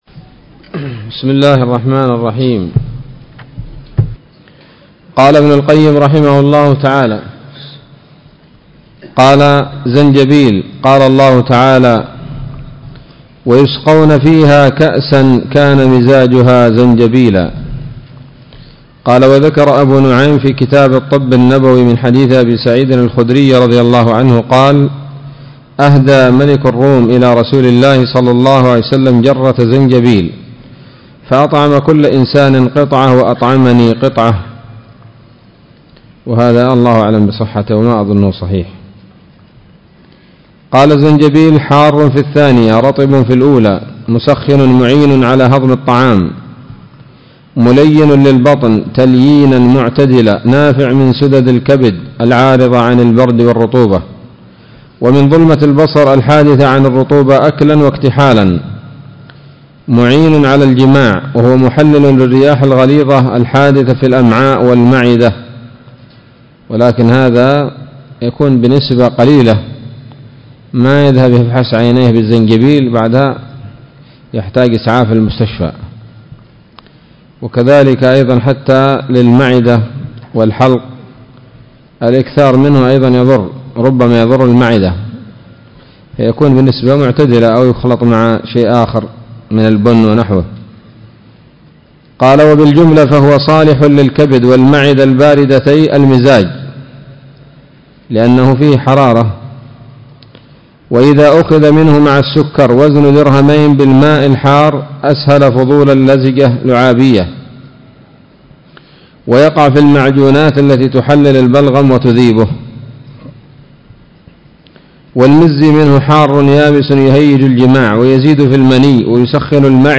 الدرس الخامس والثمانون من كتاب الطب النبوي لابن القيم